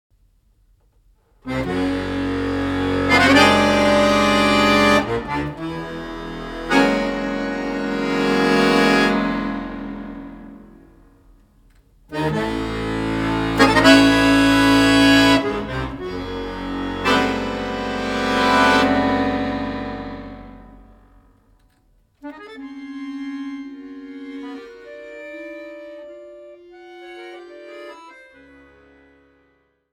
Accordion Music